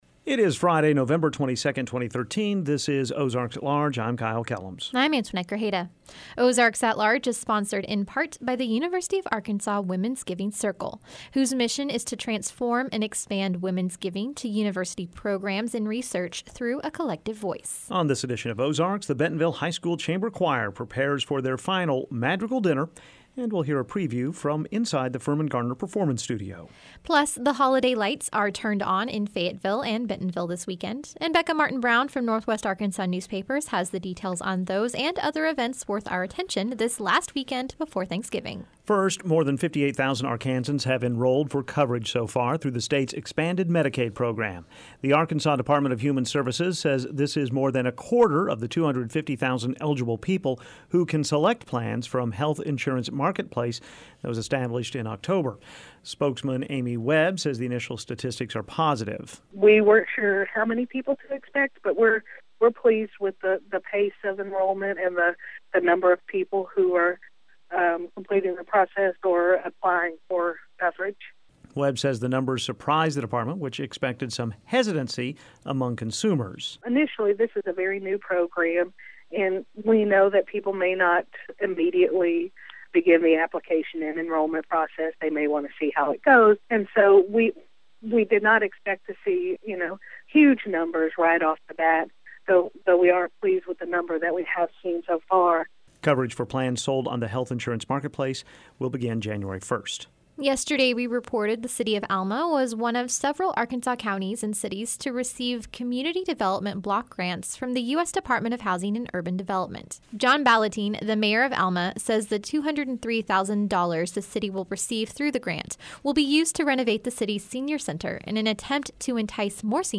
On this edition of Ozarks, the Bentonville High School Chamber Choir prepares for their final madrigal dinner and we’ll hear a preview from the Firmin Garner Performance Studio.